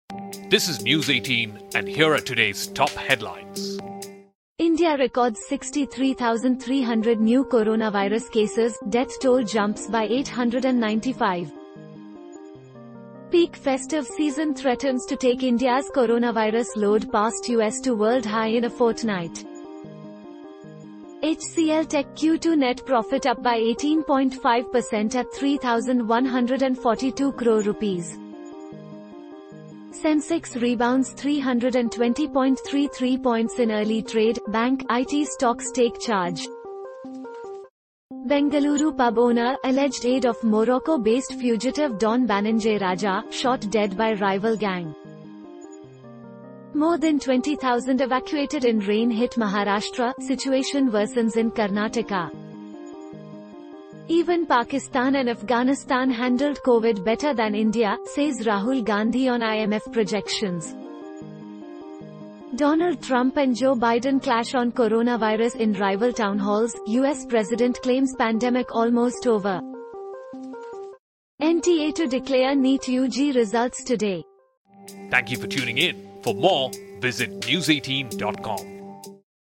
Catch up with the top headlines of the day with our Audio Bulletin, your daily news fix in under 2 minutes.